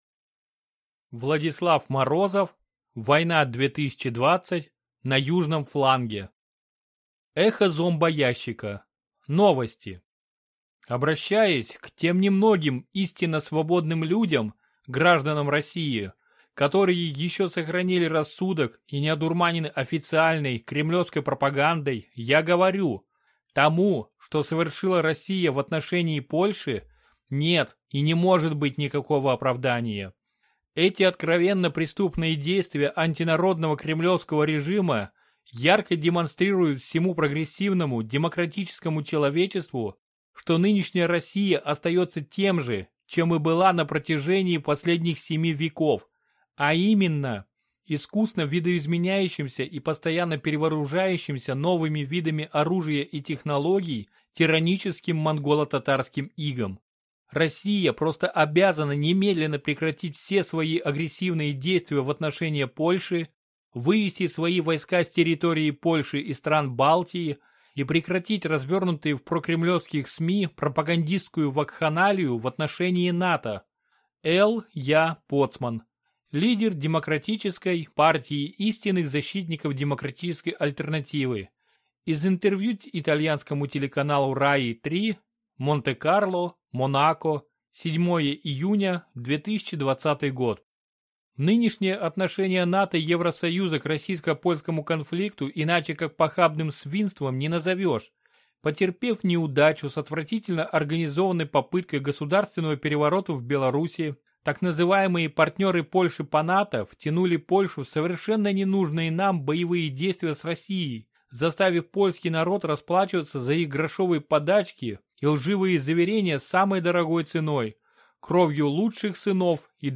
Аудиокнига Война 2020. На южном фланге | Библиотека аудиокниг